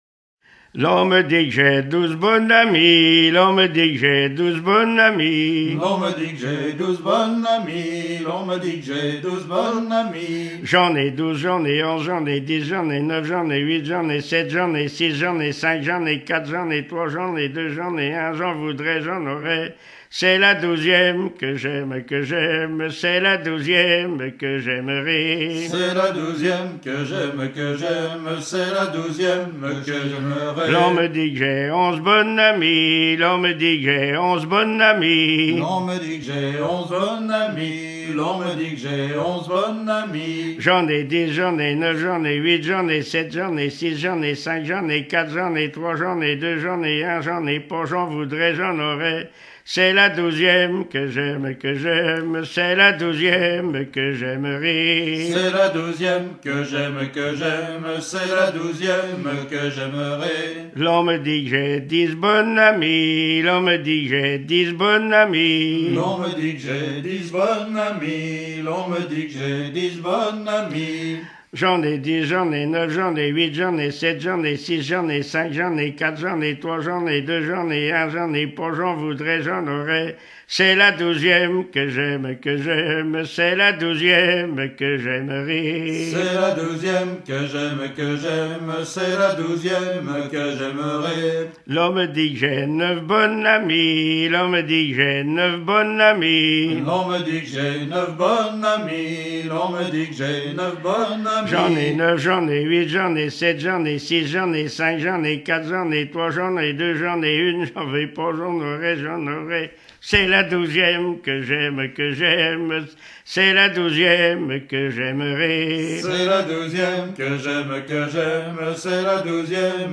Localisation Saint-Martin-des-Noyers
circonstance : bachique
Genre énumérative